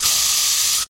Звуки баллончика с краской
На этой странице собраны звуки баллончика с краской, которые передают процесс создания граффити: от характерного стука шарика при встряхивании до равномерного шипения при распылении.
Шепот распыления